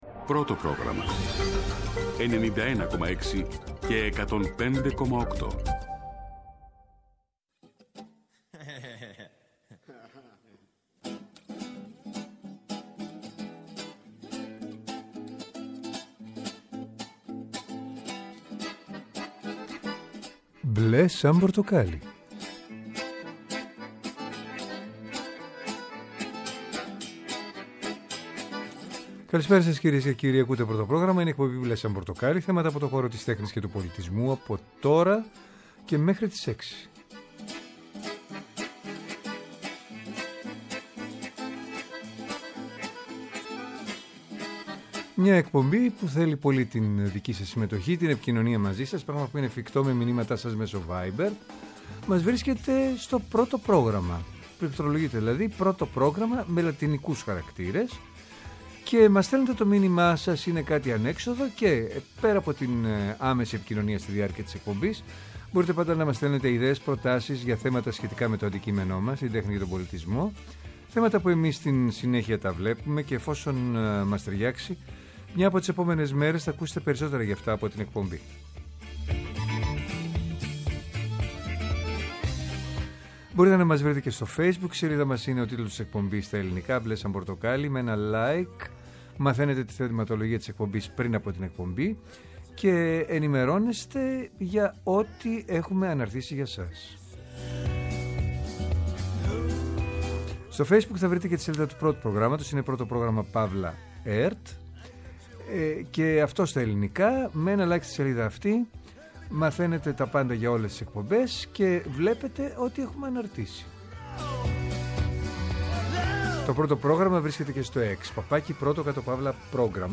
Μια εκπομπή με εκλεκτούς καλεσμένους, άποψη και επαφή με την επικαιρότητα.